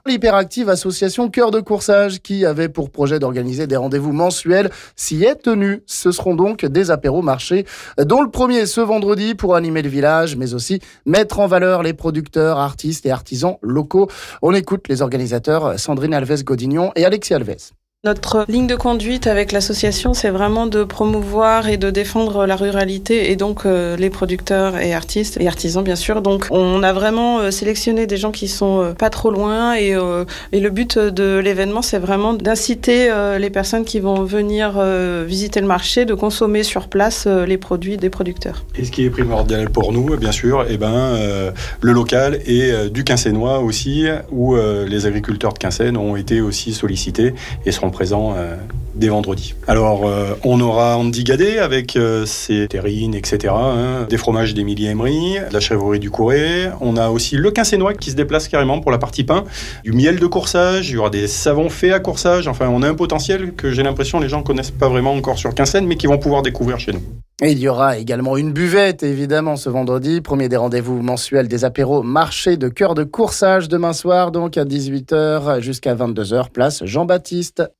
On écoute ici les organisateurs